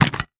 handgun_B_empty.wav